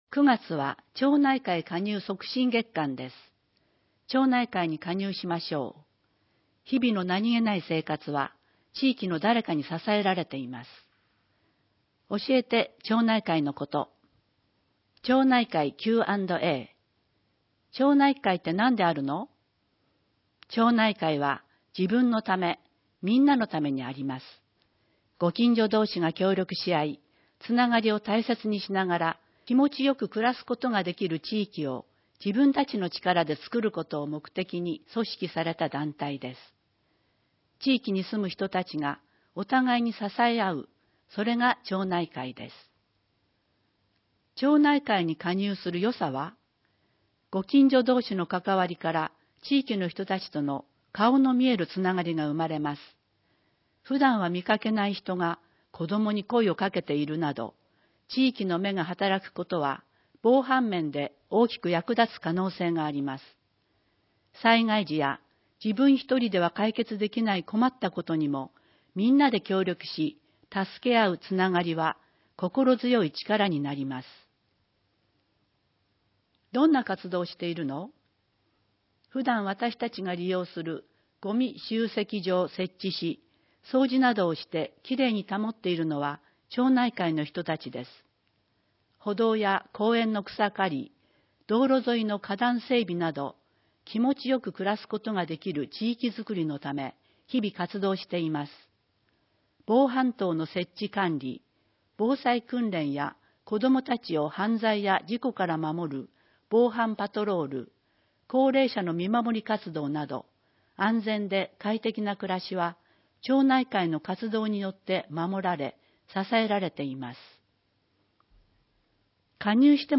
音声は、ボランティアグループ「やまびこの会」が朗読録音したものです。